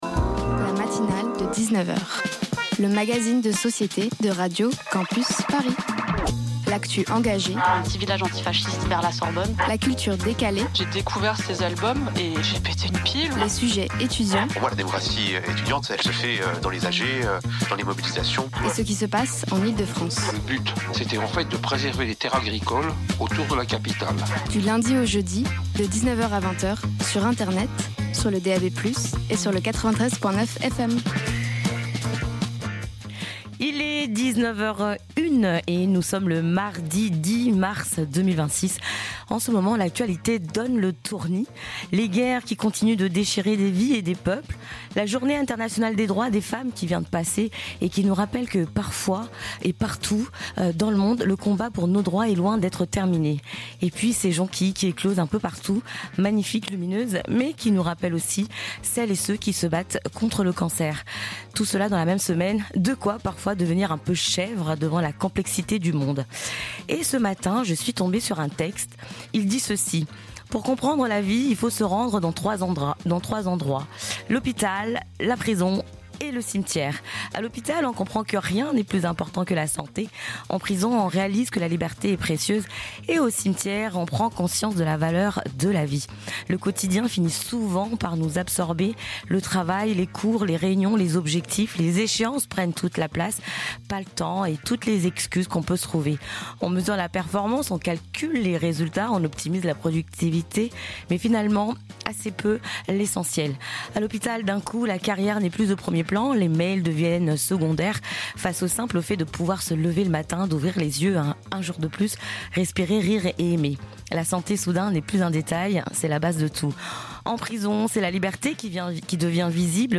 Type Magazine Société Culture